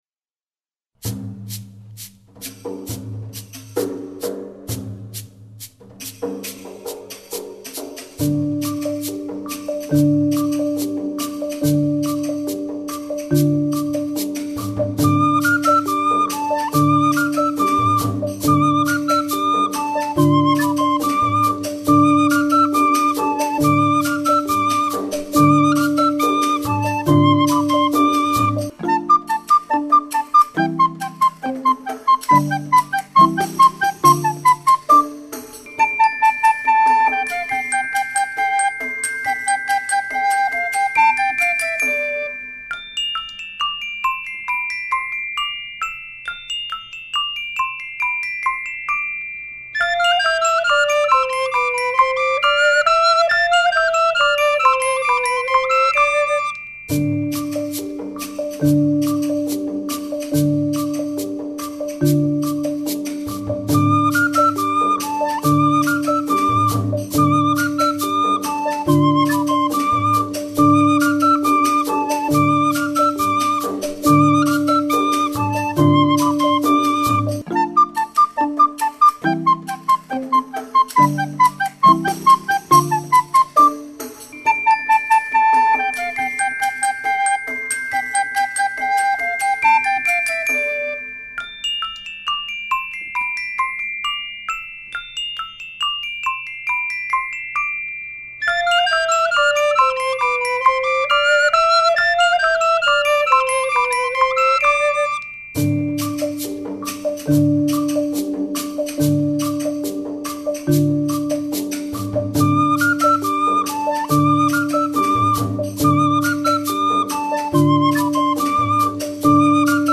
ΑΦΗΓΗΣΗ ΠΑΡΑΜΥΘΙΟΥ